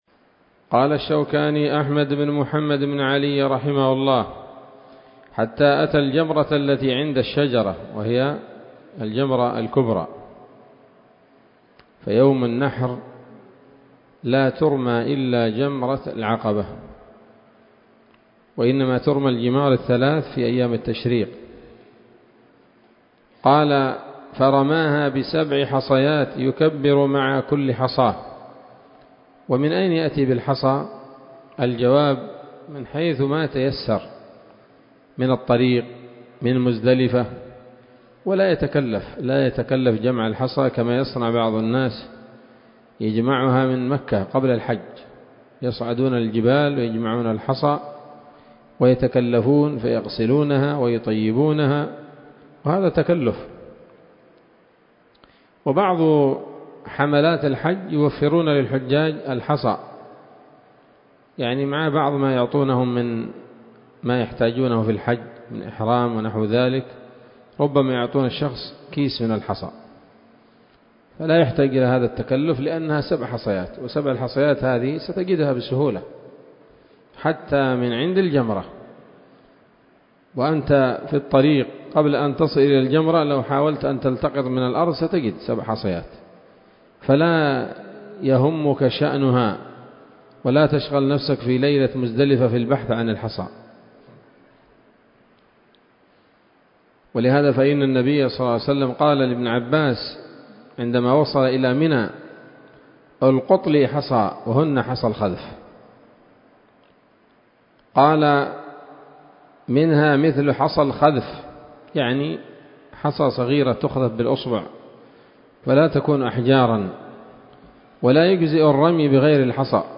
الدرس الحادي والعشرون من كتاب الحج من السموط الذهبية الحاوية للدرر البهية